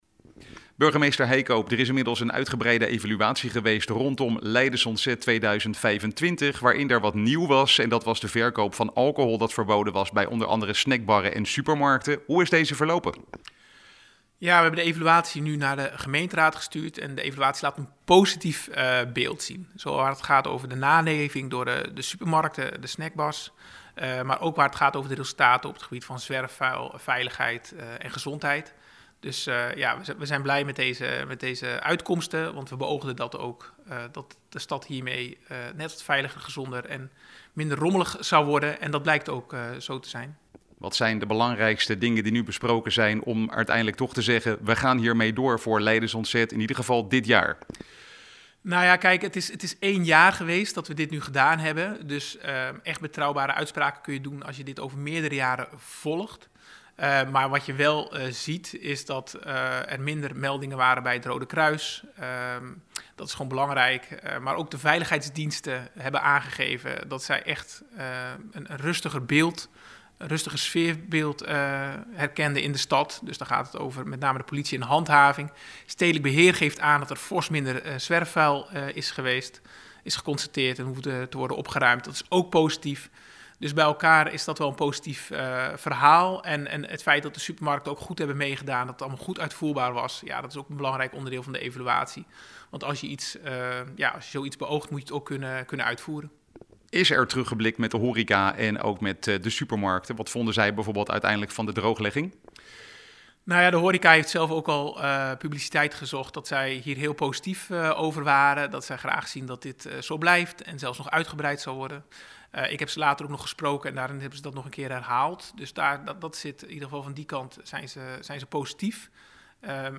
Burgemeester Peter Heijkoop over geen alcoholverkoopverbod tijdens Koningsdag en Lakenfeesten: